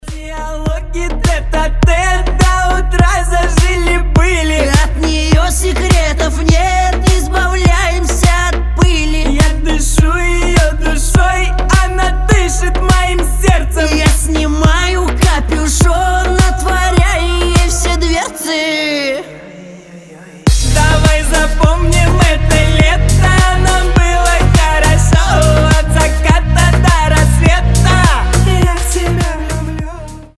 мужской голос
дуэт